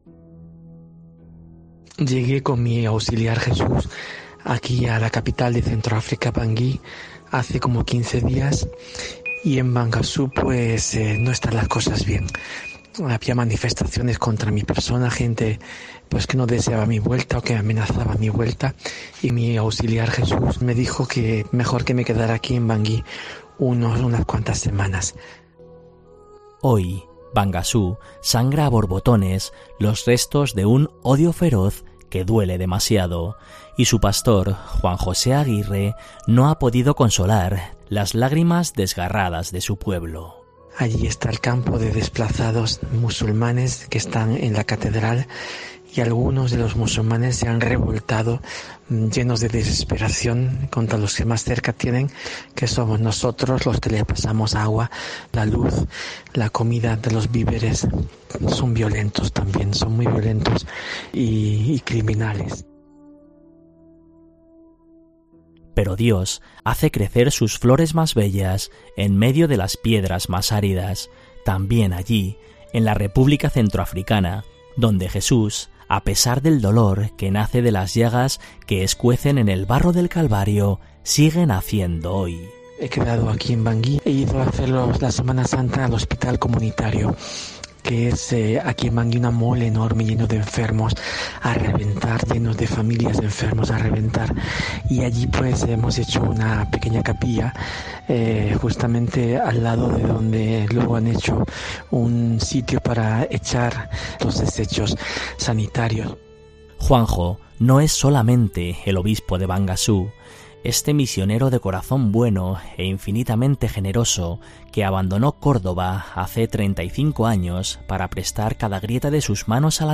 Este es el mensaje que nos ha hecho llegar monseñor Juan José Aguirre, M.C.C.J., obispo de Bangassou, en la República Centroafricana.